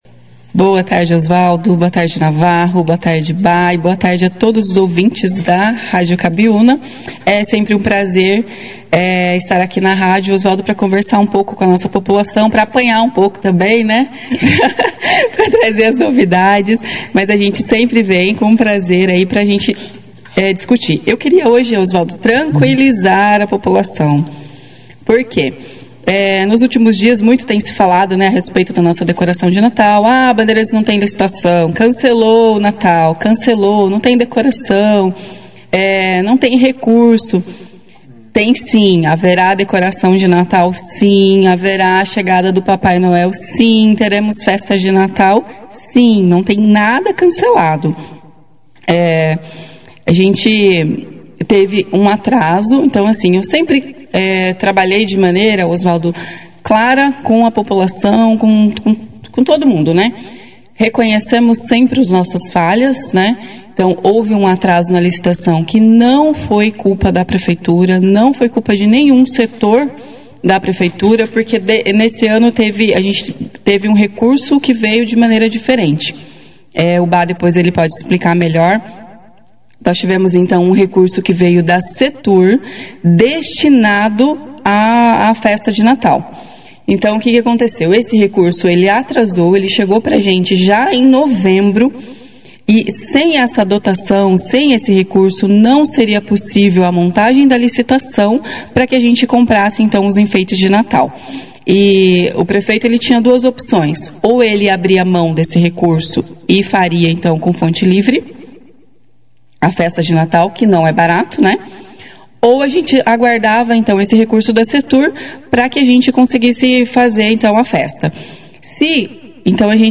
A Secretária de Administração de Bandeirantes, Claudia Jans, e o Diretor de Cultura, Everton Bonfim Romano (Bhá), participaram da 2ª edição do jornal Operação Cidade nesta sexta-feira, dia 5 de dezembro, para prestar esclarecimentos sobre a decoração, cantatas e a chegada do Papai Noel.